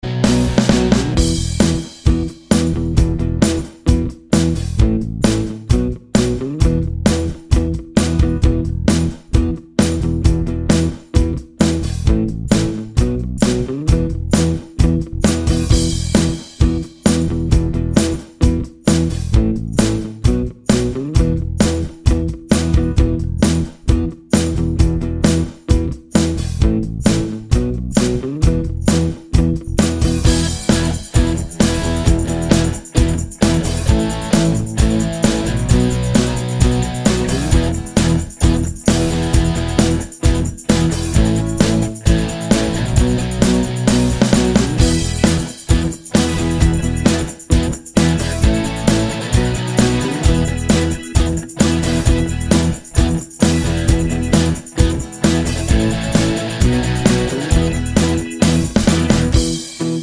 no Backing Vocals at all Pop (1980s) 3:34 Buy £1.50